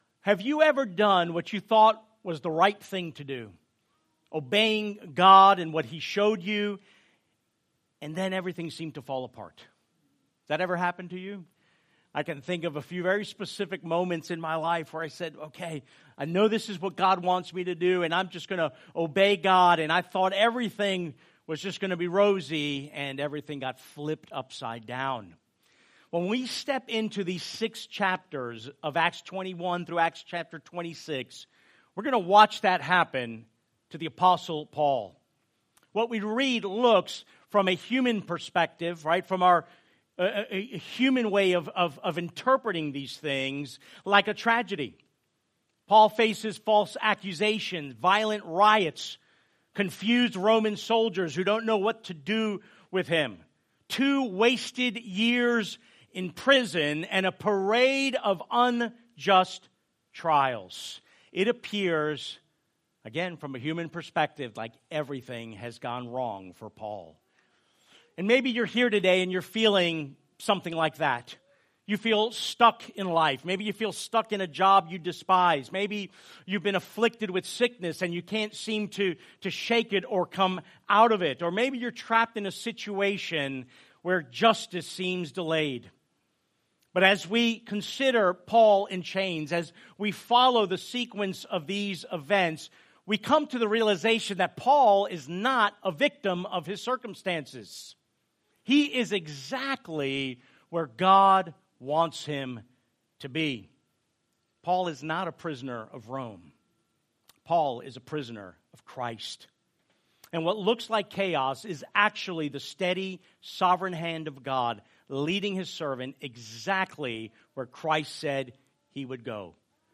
Sent Church Lake Mary, Longwood, Sanford FL | Sermons